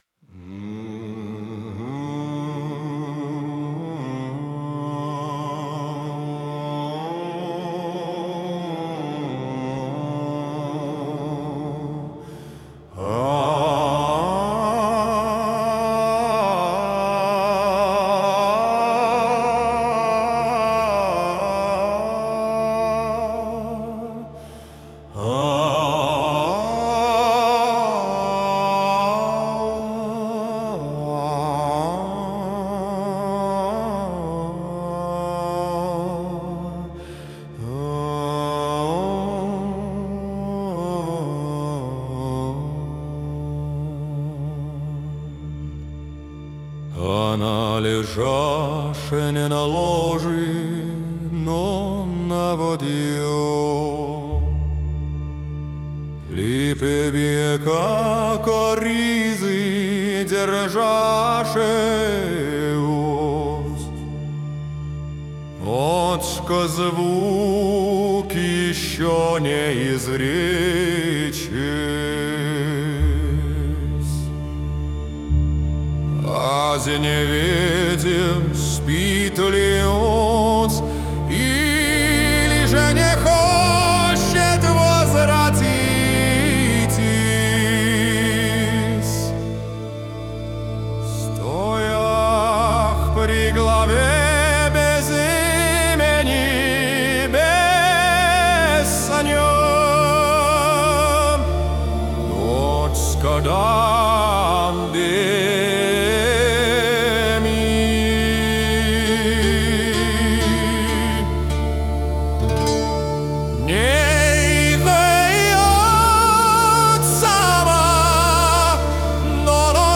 somatopoème